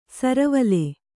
♪ saravale